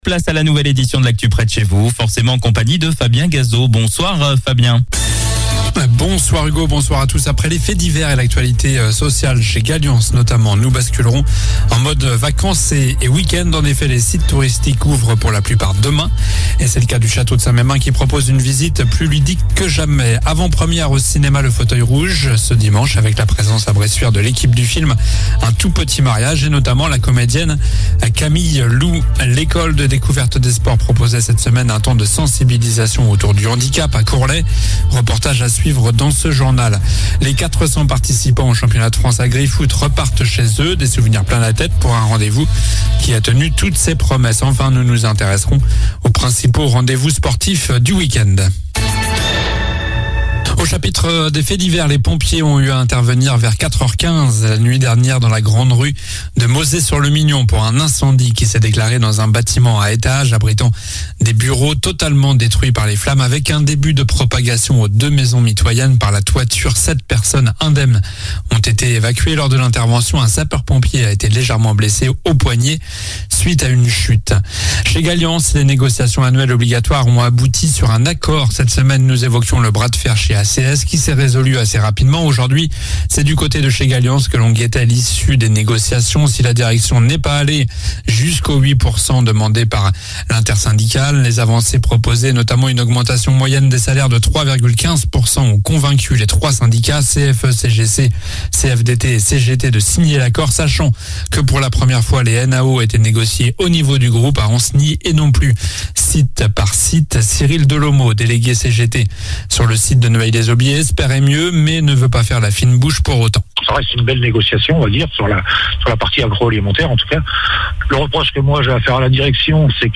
Journal du vendredi 7 avril (soir)